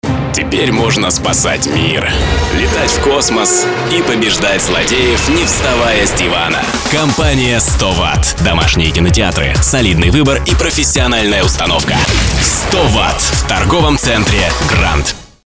Портфолио | Радио реклама | Аудио реклама | Радио ролик | Аудио ролик